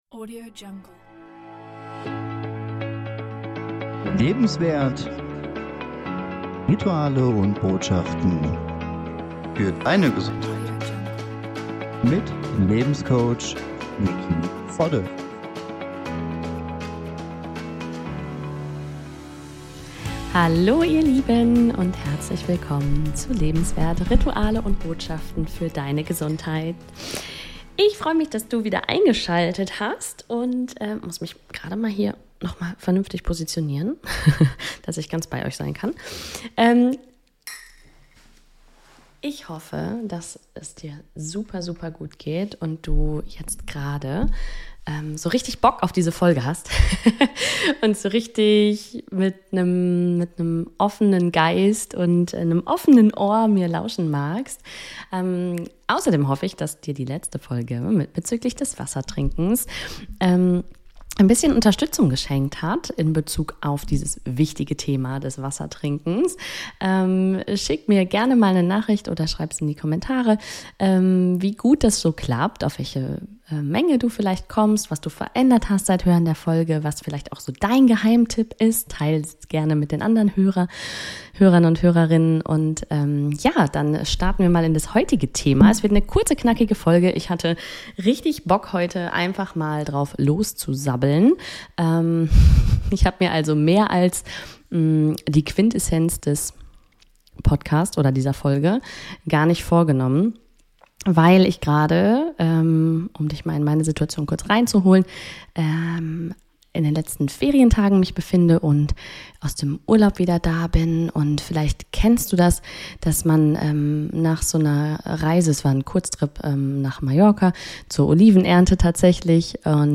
Hier eine kleine Kerzenmeditation als Übung für mehr Selbstliebe im Alltag. In Verbindung mit mutmachenden, nährenden Affirmationen schenkt diese Folge dir einen wunderschönen Me Time Moment für den Alltag!